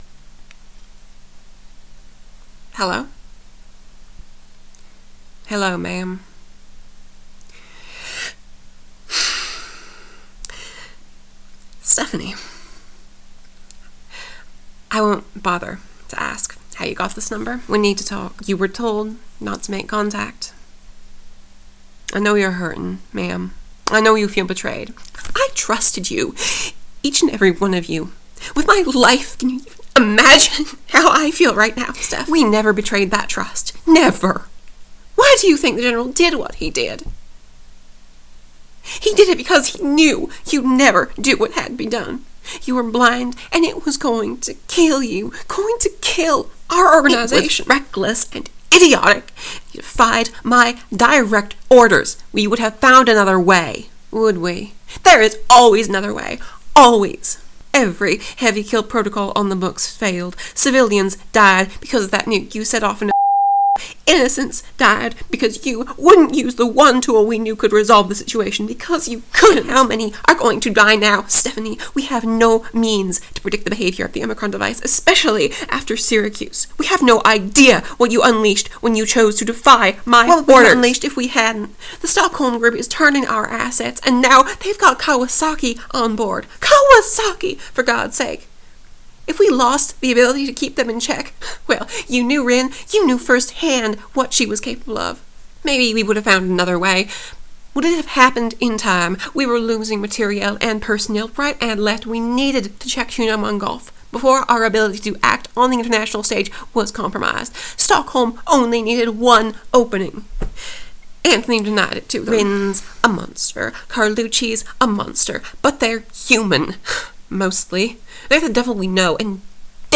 Intercepted Phone Call 05 AUGUST 2017 - 1039 (Transcript)
This is a rush transcript of an automatically intercepted call.